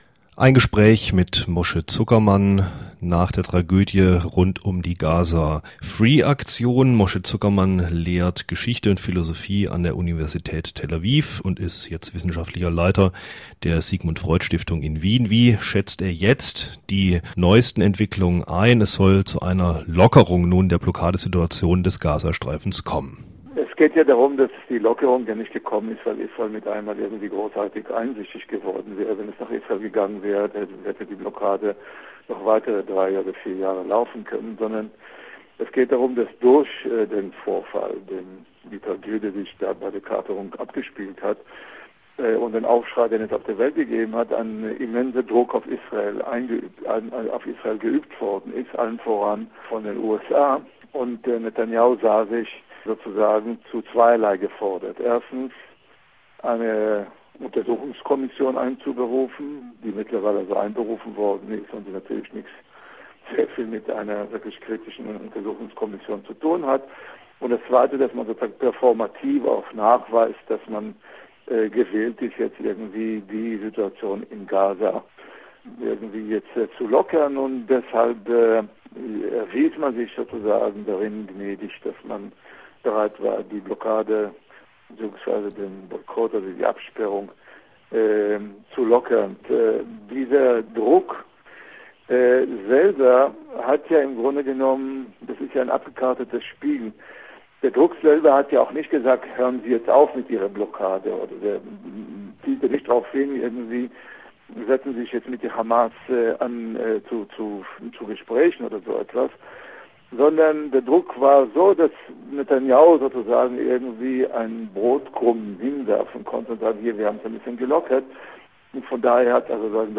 Nach der Tragödie um die Stürmung der Gaza-Flotilla gibt der israelische Soziologe und Historiker Moshe Zuckermann im Interview